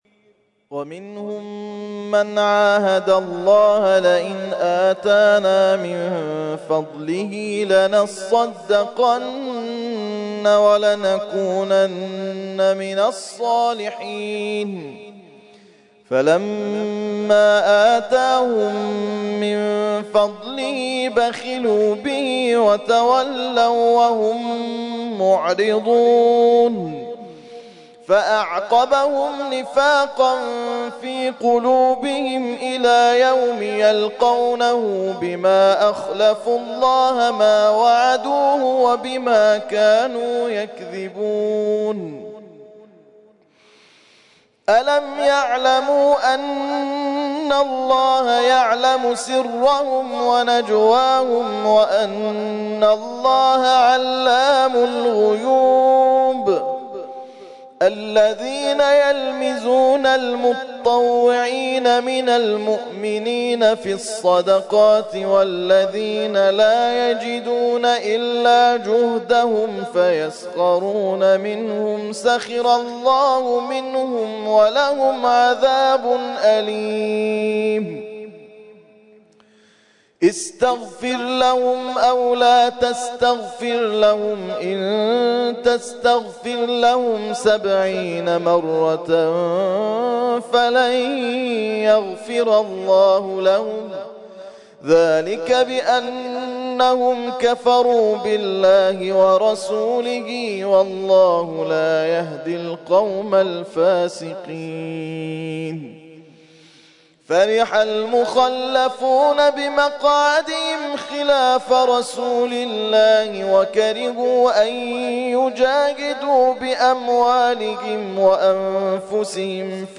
ترتیل خوانی جزء ۱۰ قرآن کریم در سال ۱۳۹۴